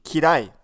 Things are pretty straight forward here, in terms of pronunciation.